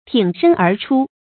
注音：ㄊㄧㄥˇ ㄕㄣ ㄦˊ ㄔㄨ
挺身而出的讀法